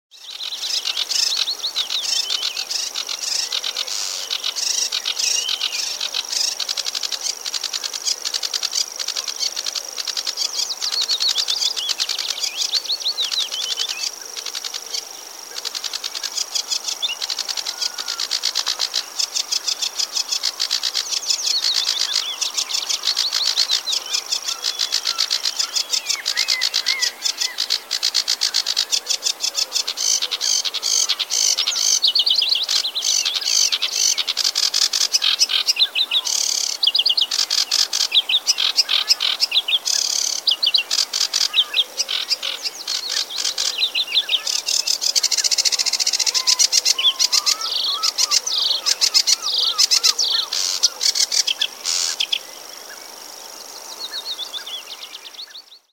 Sedge warbler Singing song Sivsanger sound effects free download
Birds in Norway